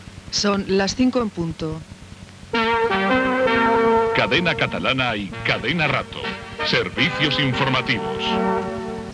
Hora i Careta Serveis Informatius.